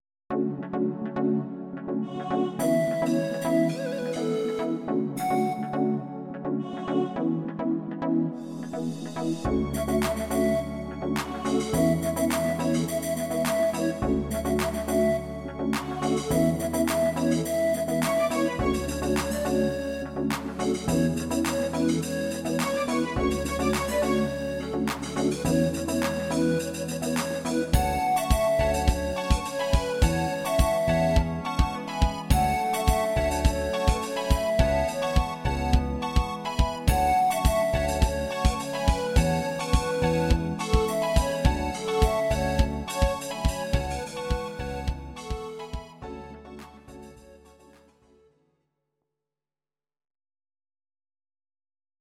Audio Recordings based on Midi-files
Ital/French/Span, 2000s